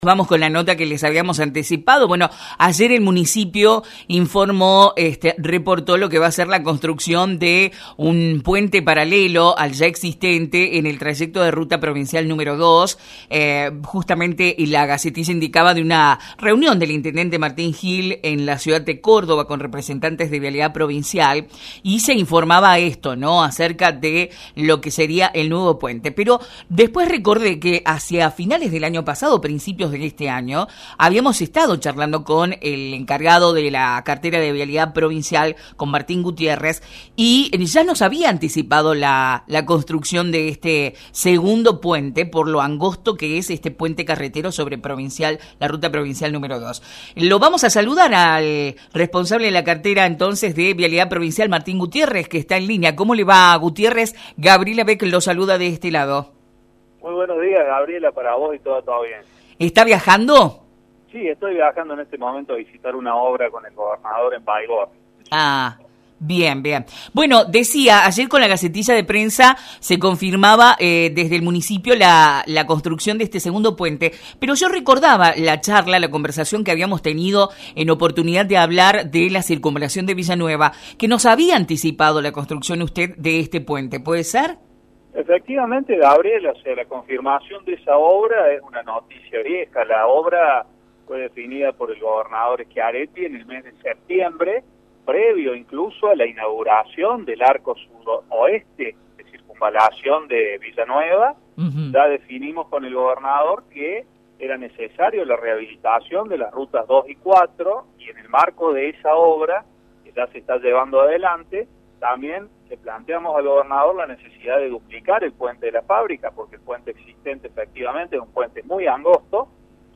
El responsable de la cartera de Vialidad de la Provincia de Córdoba, Martín Gutiérrez, en diálogo con «La Mañana Informal» de Radio Centro, estuvo repasando algunas de las obras que el gobierno provincial está llevando en nuestra zona, tanto en Villa María como Villa Nueva.